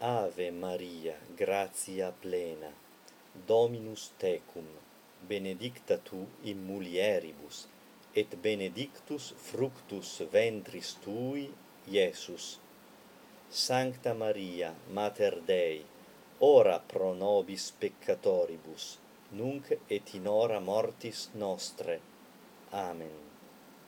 교회 발음 라틴어 기도문 ''Ave Maria''